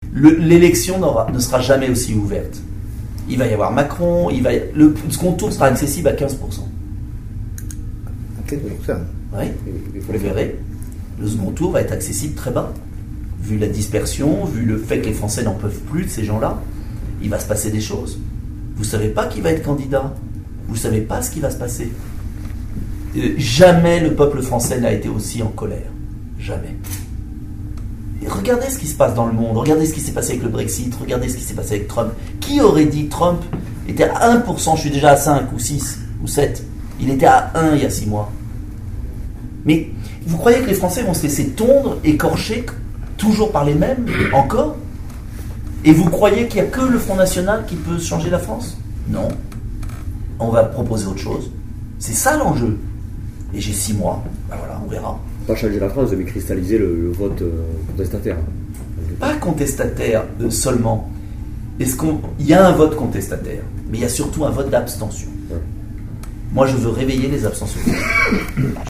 La conférence de presse
A son arrivée à l’aéroport de Tarbes-Lourdes-Pyrénées, Nicolas Dupont-Aignan a tenu une conférence de presse dans une salle de l’aérogare d’affaires.